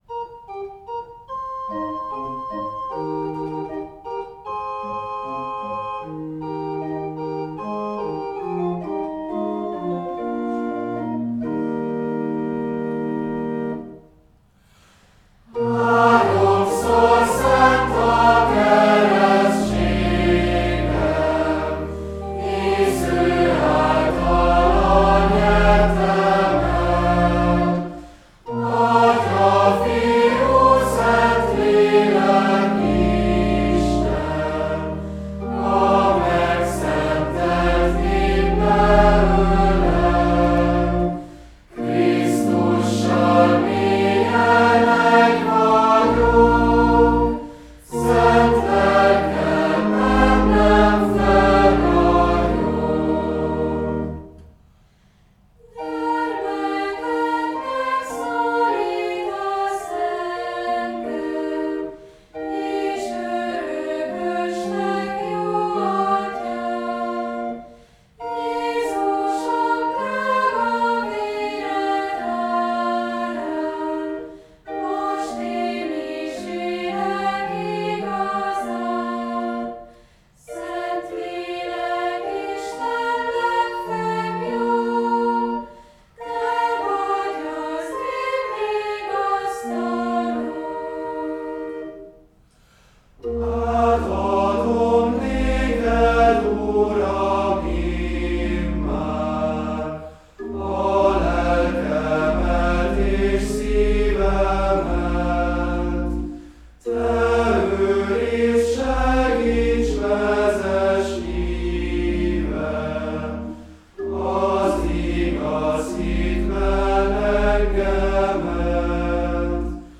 A szöveghez társított dallam – Ó, bárcsak ezer nyelvem volna – igen magabiztos dallamjárású, már-már indulószerű.